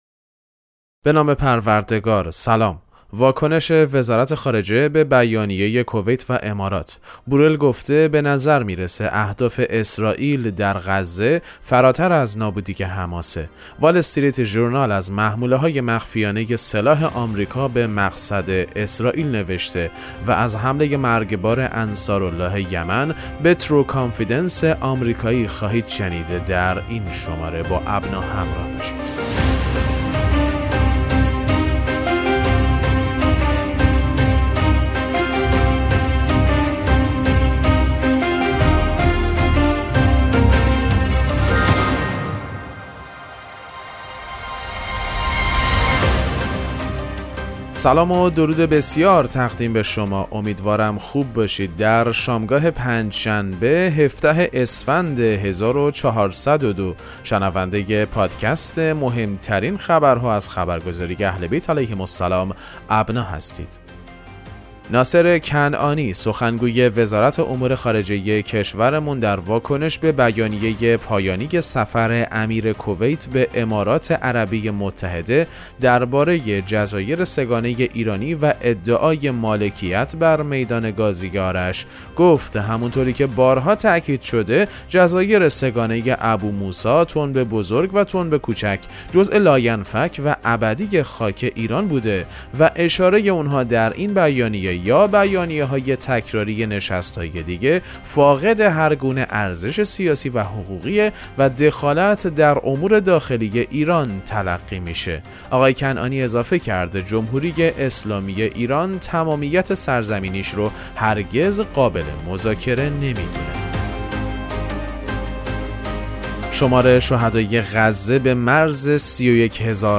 پادکست مهم‌ترین اخبار ابنا فارسی ــ 17 اسفند 1402